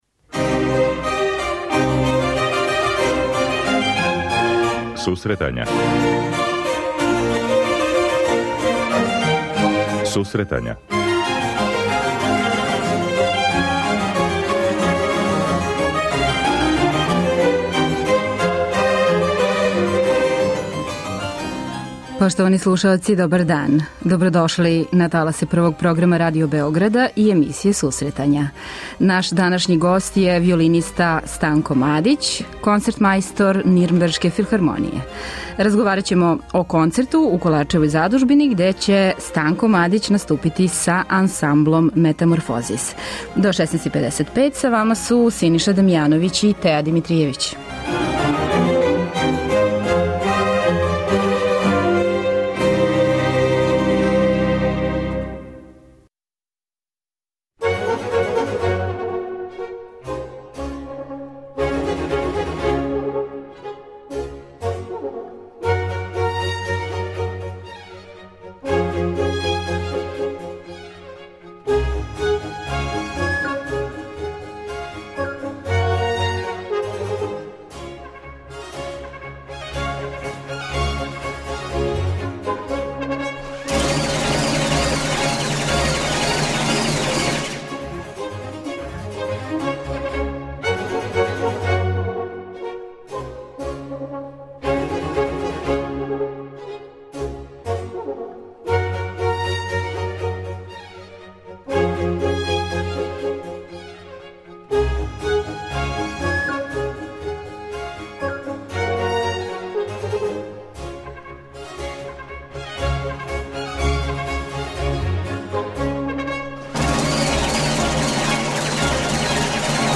преузми : 26.09 MB Сусретања Autor: Музичка редакција Емисија за оне који воле уметничку музику.